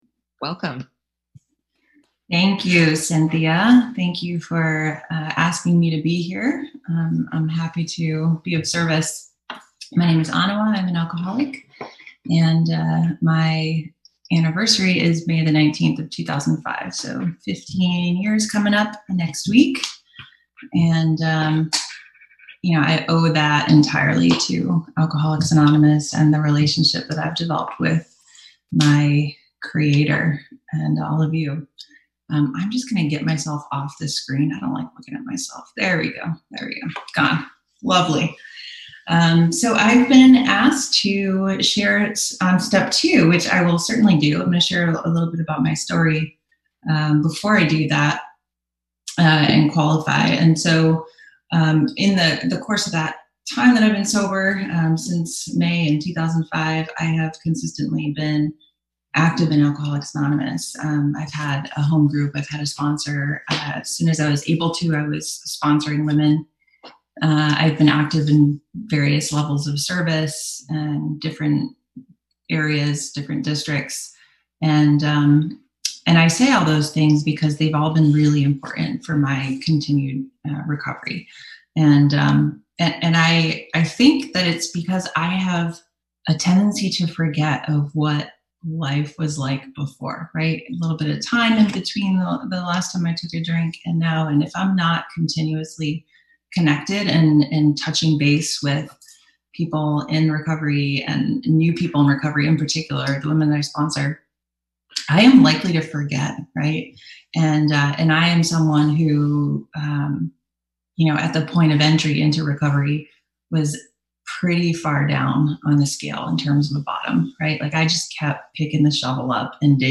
Women's Wisdom AA Weekend